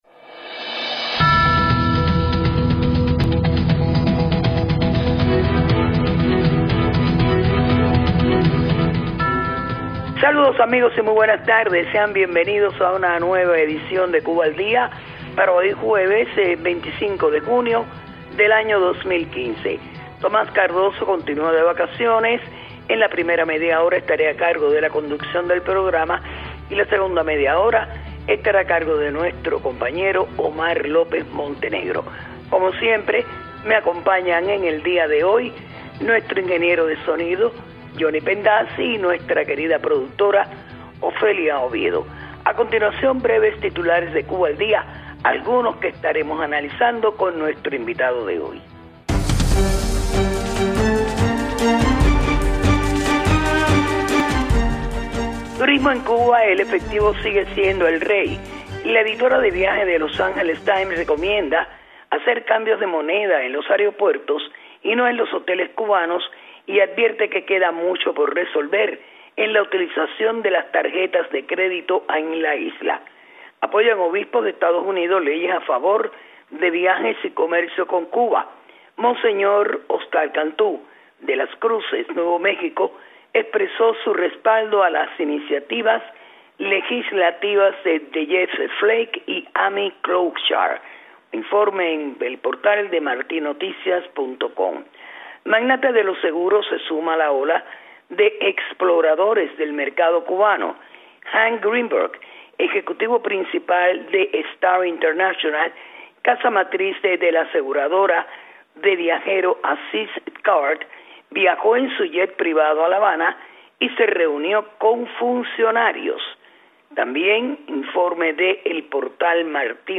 Entrevistas
La periodista